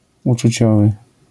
wymowa:
IPA[ˌut͡ʃuˈt͡ɕɔvɨ], AS[učućovy], zjawiska fonetyczne: zmięk.akc. pob.